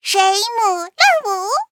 文件 文件历史 文件用途 全域文件用途 Chorong_skill_03.ogg （Ogg Vorbis声音文件，长度1.7秒，112 kbps，文件大小：23 KB） 源地址:地下城与勇士游戏语音 文件历史 点击某个日期/时间查看对应时刻的文件。